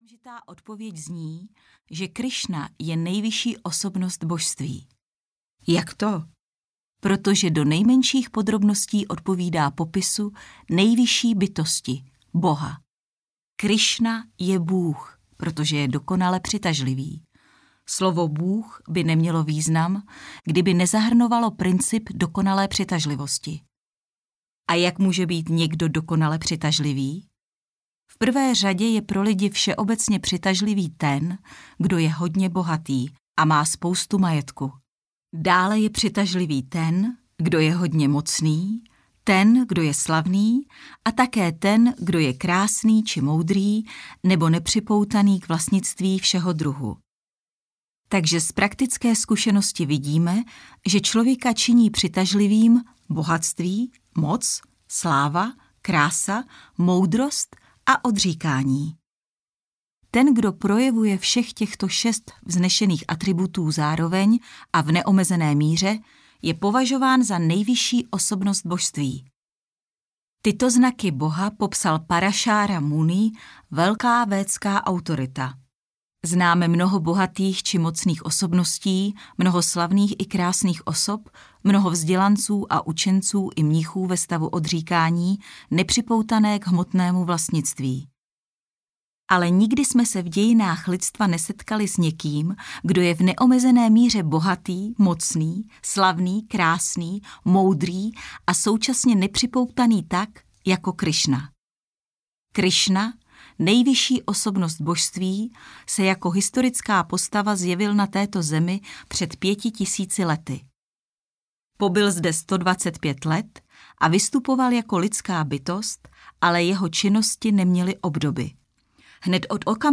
Ukázka z knihy
• InterpretNela Boudová